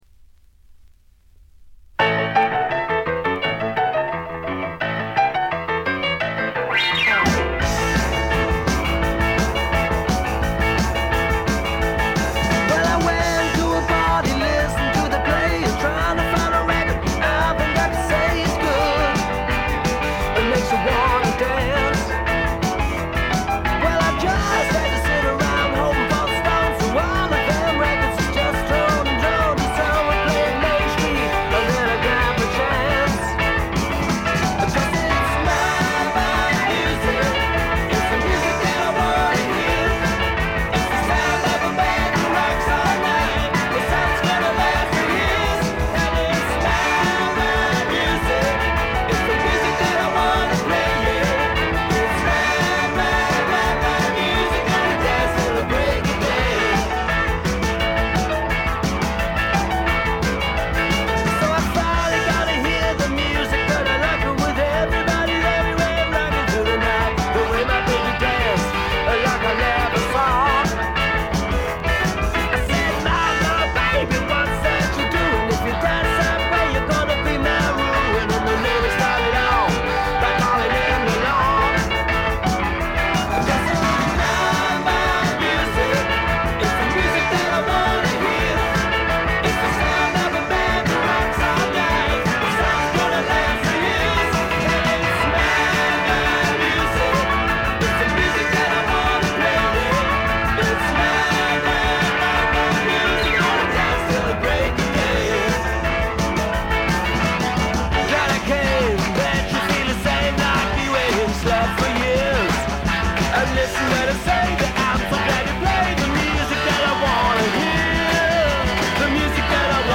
ごくわずかなノイズ感のみ。
ほのかない香るカントリー風味に、何よりも小粋でポップでごきげんなロックンロールが最高です！
試聴曲は現品からの取り込み音源です。
Rockfield Studios, South Wales